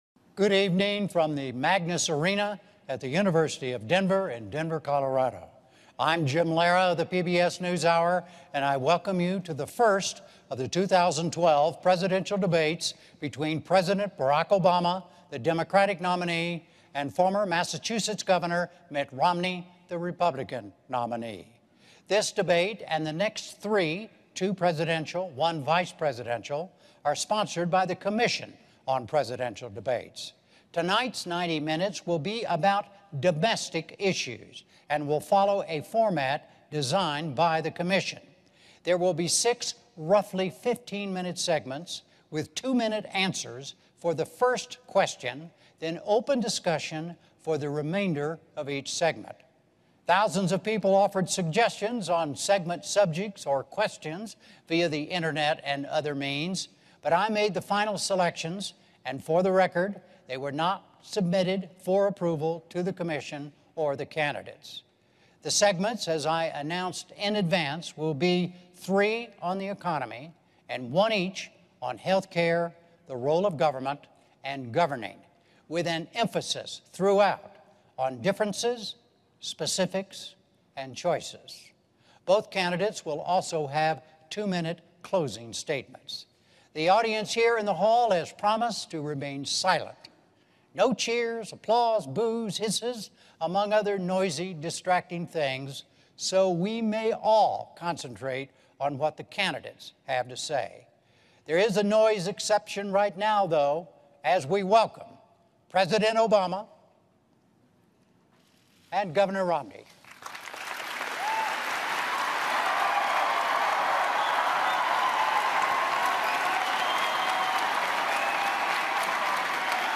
U.S. President Barack Obama and Republican challenger Mitt Romney meet in their first debate of the 2012 presidential campaign